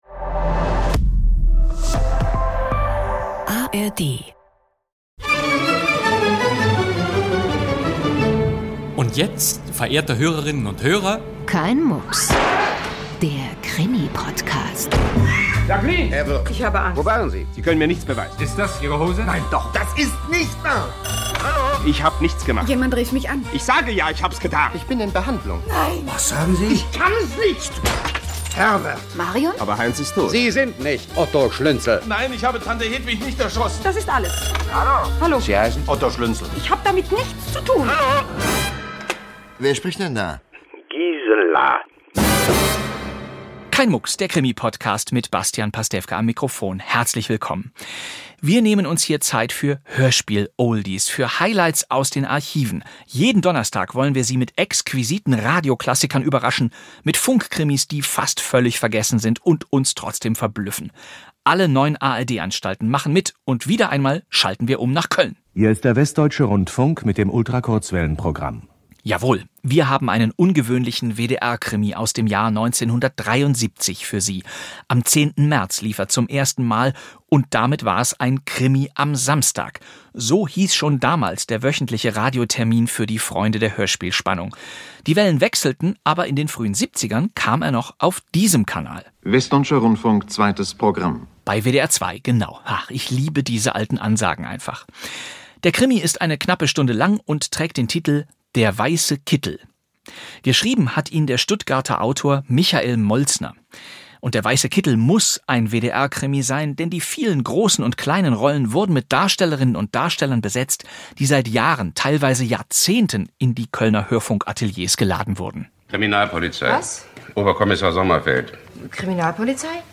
Der weiße Kittel. Krimi-Podcast mit Bastian Pastewka ~ Kein Mucks!